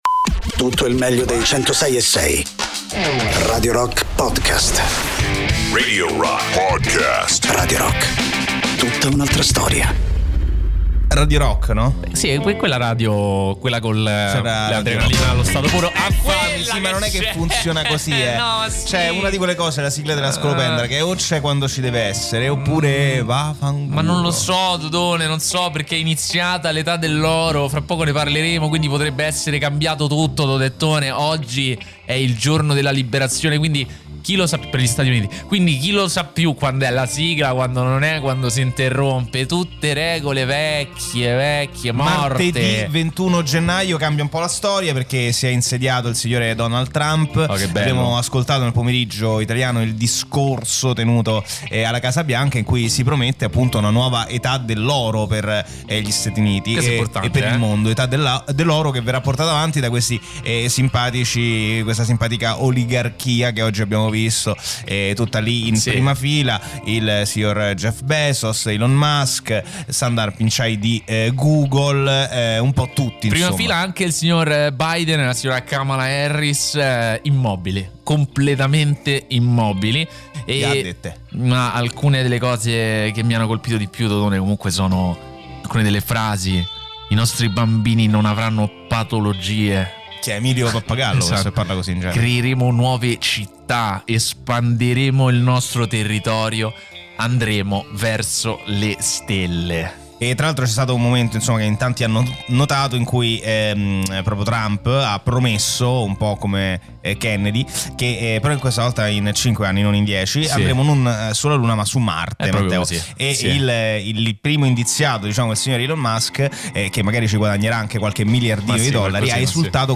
in diretta da Lunedì a Giovedì da mezzanotte all’1